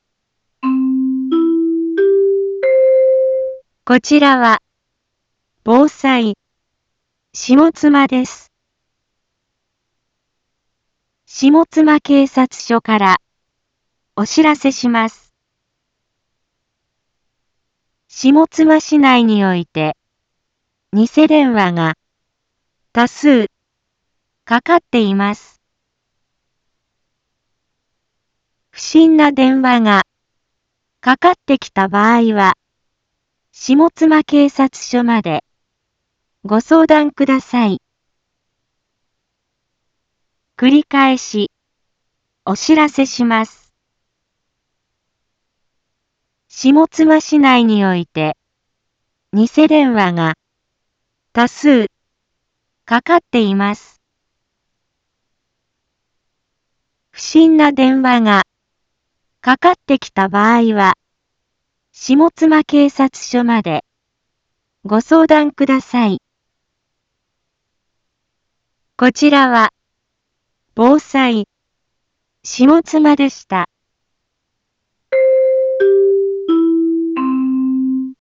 一般放送情報
Back Home 一般放送情報 音声放送 再生 一般放送情報 登録日時：2024-02-04 10:01:21 タイトル：ニセ電話詐欺にご注意を インフォメーション：こちらは、防災、下妻です。